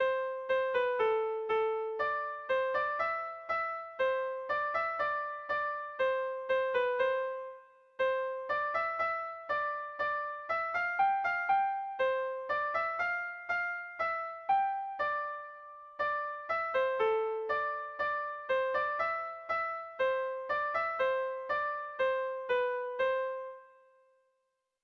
Air de bertsos - Voir fiche   Pour savoir plus sur cette section
Sentimenduzkoa
ABA